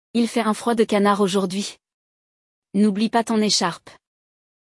No episódio, você vai ouvir uma conversa entre falantes nativos e entender como essa expressão se encaixa em diferentes situações.